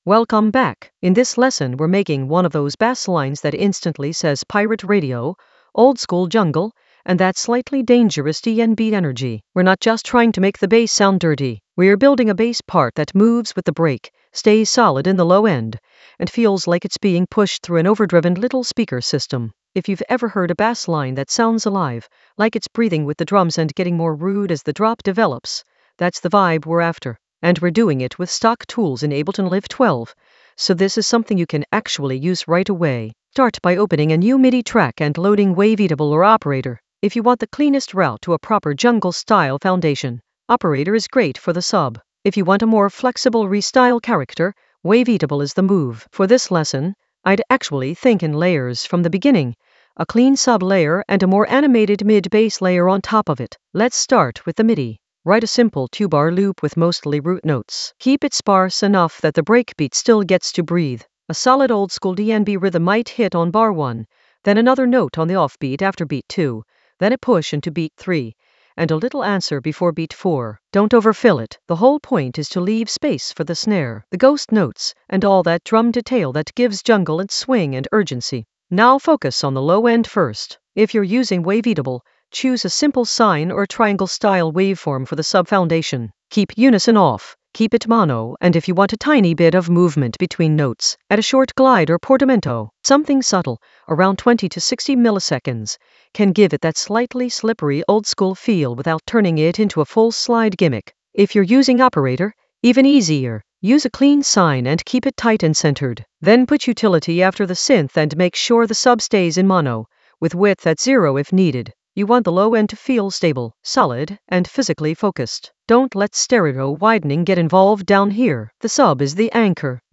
An AI-generated intermediate Ableton lesson focused on Bassline Theory: bass wobble saturate for pirate-radio energy in Ableton Live 12 for jungle oldskool DnB vibes in the Automation area of drum and bass production.
Narrated lesson audio
The voice track includes the tutorial plus extra teacher commentary.